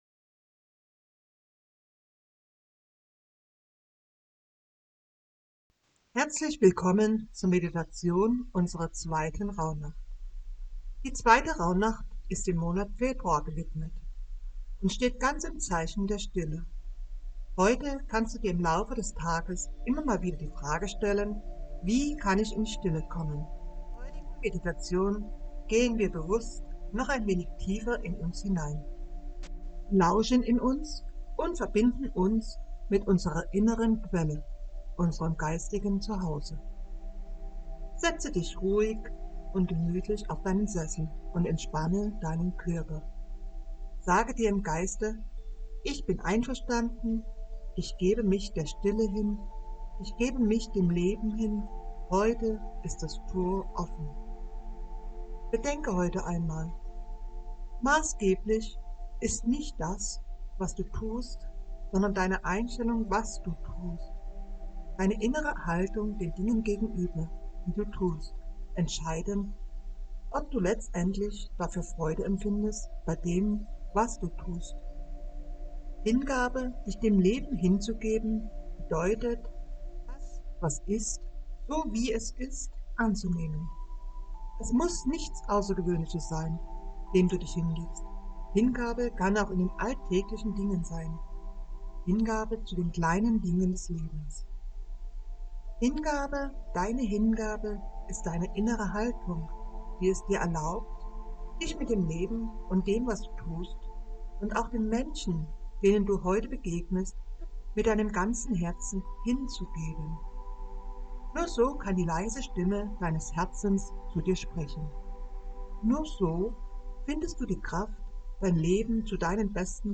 Meditation zur zweiten Rauhnacht Die heutigen Clearings sind: Was hast du daran so lebenswichtig gemacht, Dinge festzuhalten, was dich daran hindert, dein Leben wirklich zu kreieren?